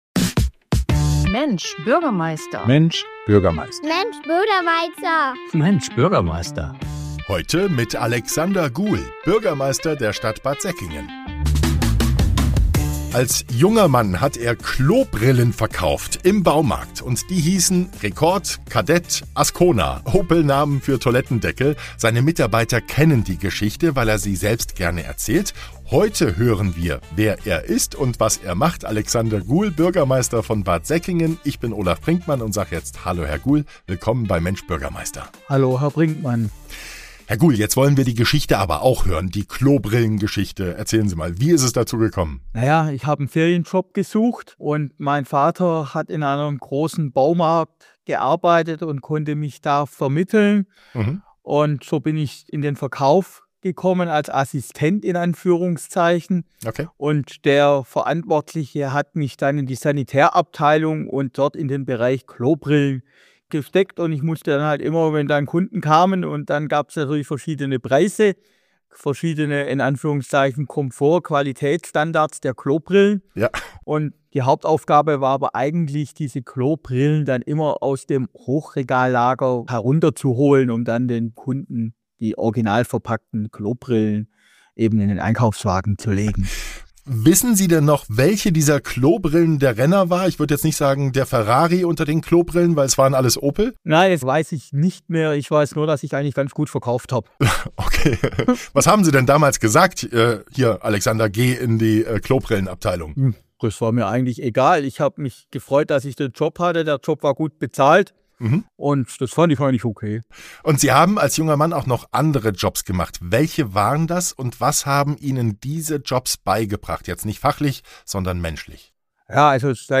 In dieser Episode von „Mensch Bürgermeister!“ ist der Bürgermeister der Stadt Bad Säckingen zu Gast und gibt persönliche Einblicke in sein...